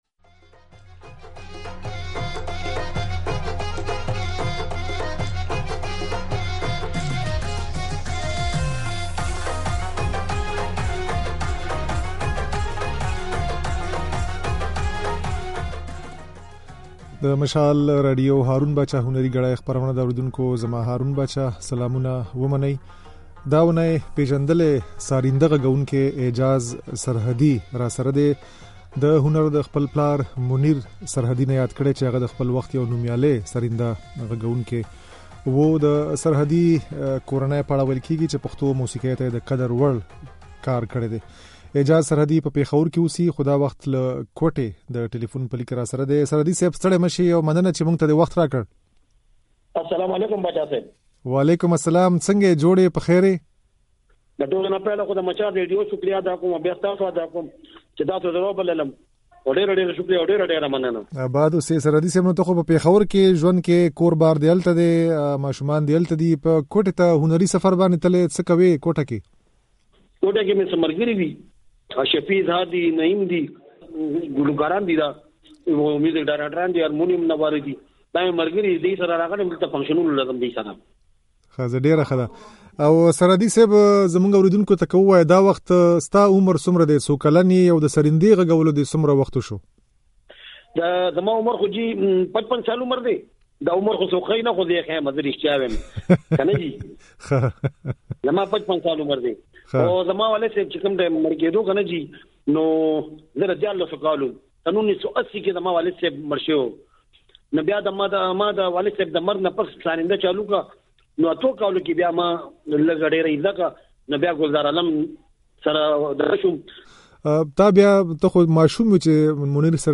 د نوموړي خبرې او د ده او د پلار د ساريندې څو نغمې د غږ په ځای کې اورېدای شئ.